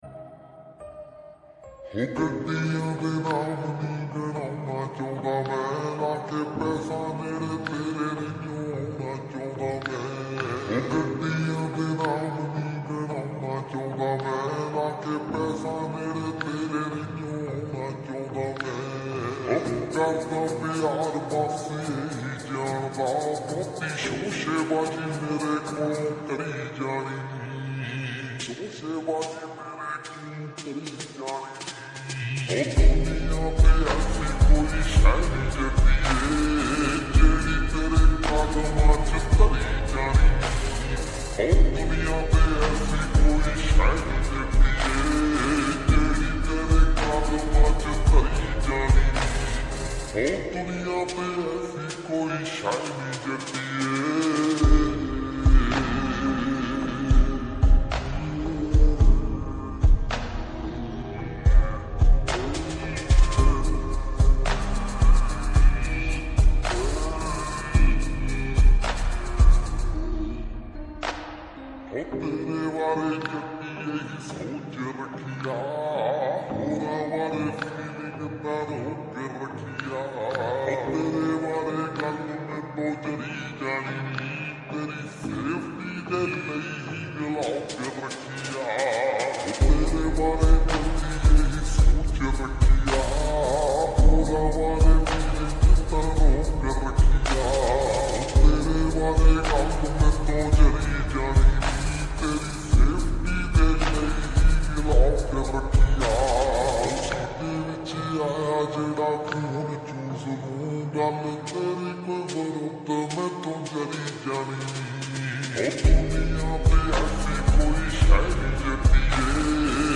PUNJABI
SLOWED SONG